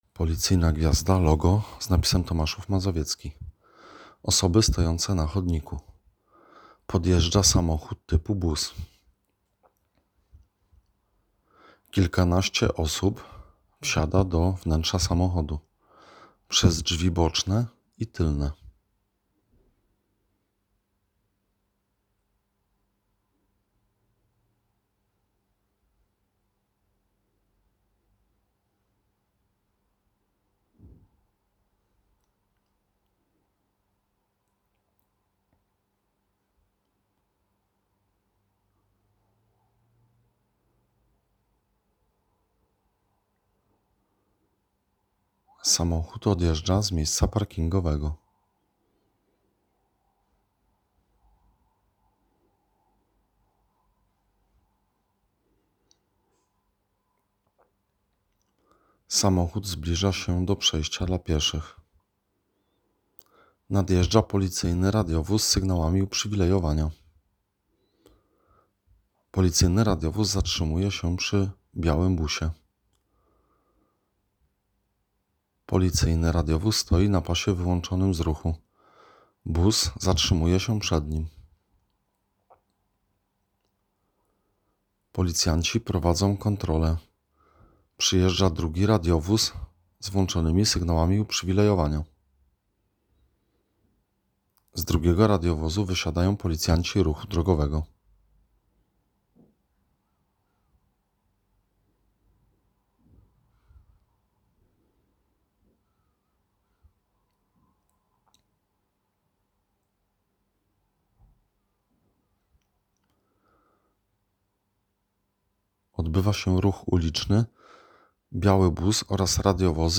Nagranie audio Audiodiodeskrypcja_do_filmu.m4a